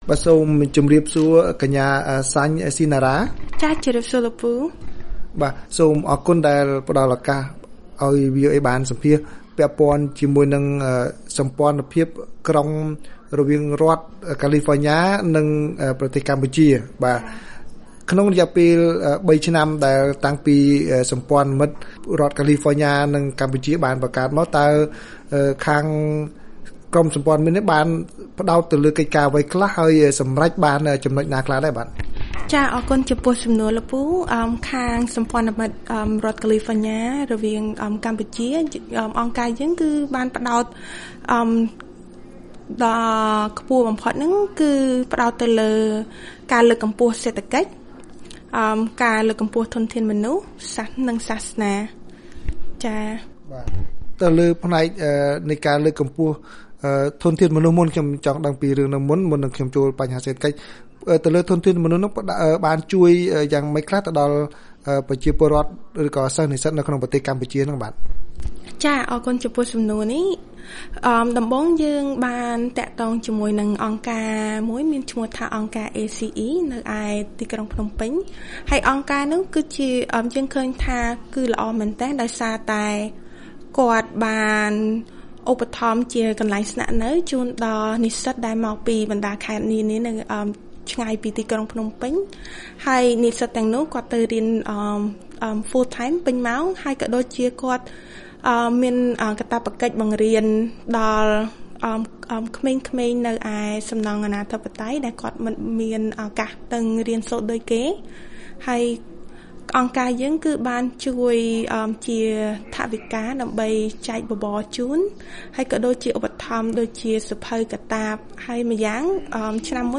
បទសម្ភាសVOA៖ សម្ព័ន្ធមិត្តរដ្ឋកាលីហ្វញ៉ានិងកម្ពុជាប្រារព្ធខួបលើកទី៣នៃសហប្រតិបត្តិការ